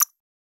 Epic Holographic User Interface Click 3.wav